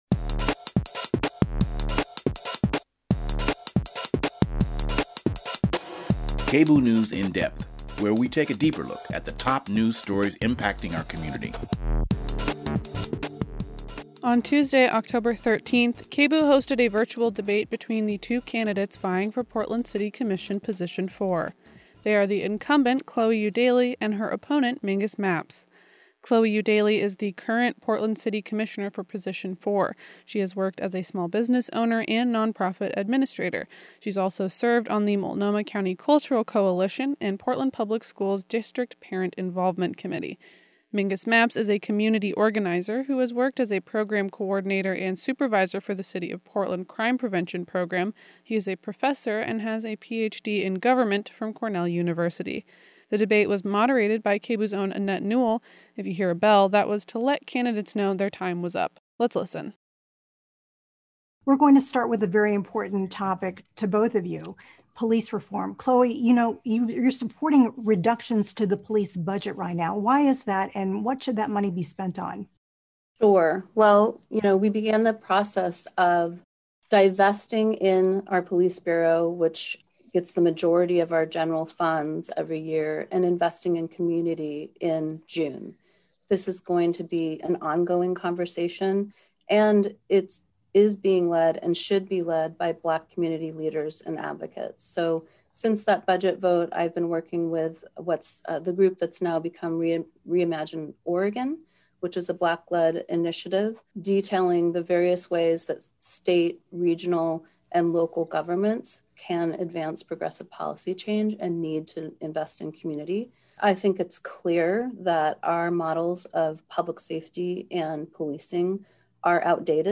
On Tuesday, October 13th, KBOO hosted a virtual debate between the two candidates vying for Portland City Commission Position 4. They are the incumbent, Chloe Eudaly, and her opponent, Mingus Mapps.